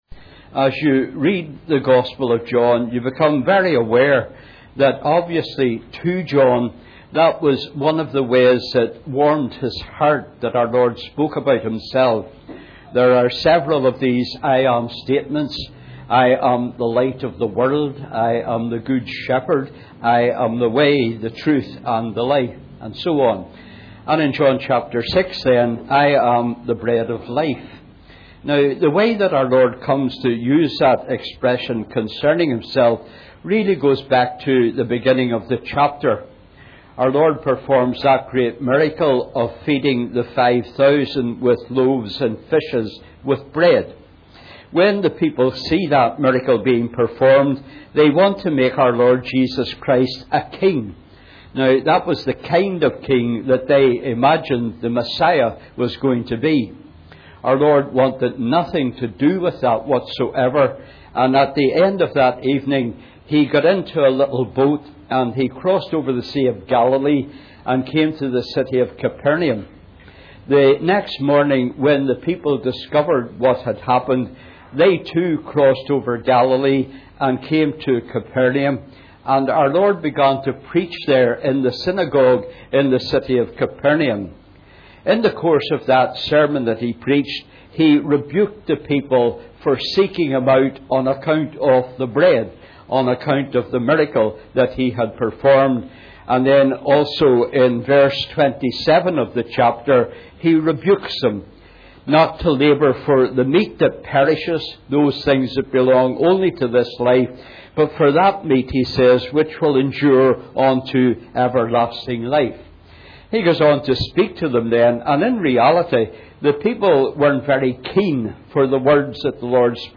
Preached on the 4th of September 2011.